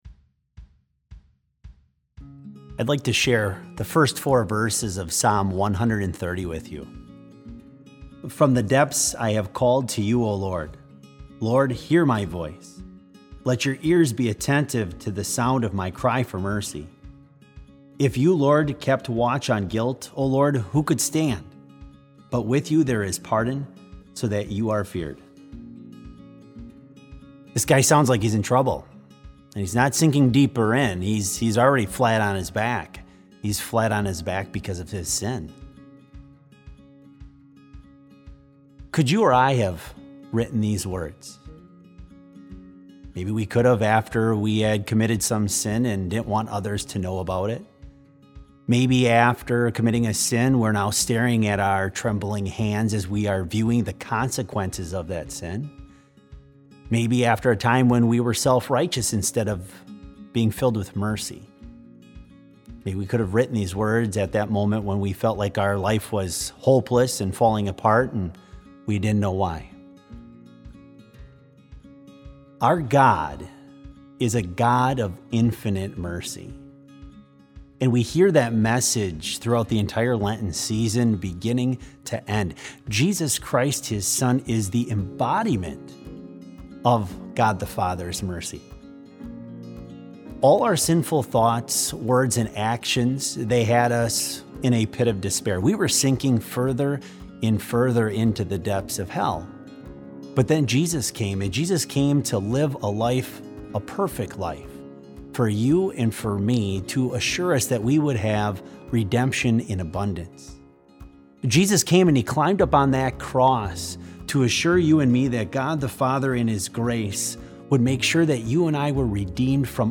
Complete Service
This Special Service was held in Trinity Chapel at Bethany Lutheran College on Friday, March 27, 2020, at 10 a.m. Page and hymn numbers are from the Evangelical Lutheran Hymnary.